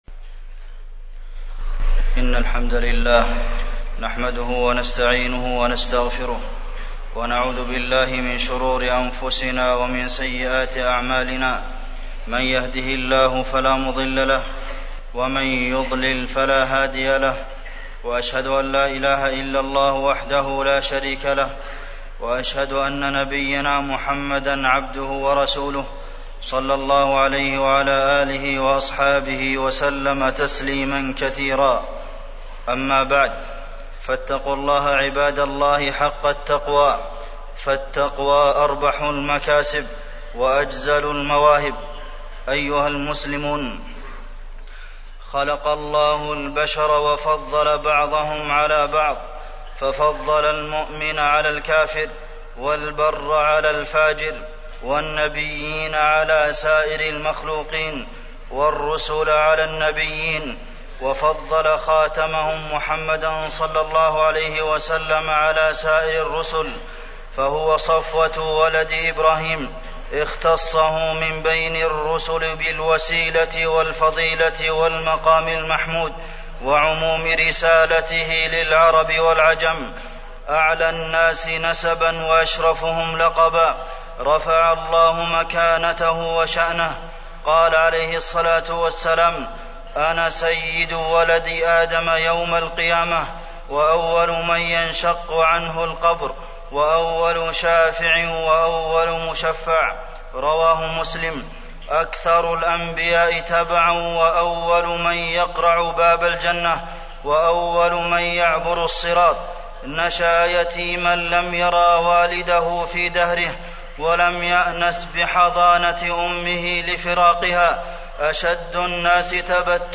تاريخ النشر ٤ محرم ١٤٢٧ هـ المكان: المسجد النبوي الشيخ: فضيلة الشيخ د. عبدالمحسن بن محمد القاسم فضيلة الشيخ د. عبدالمحسن بن محمد القاسم عظم قدر النبي عليه الصلاة والسلام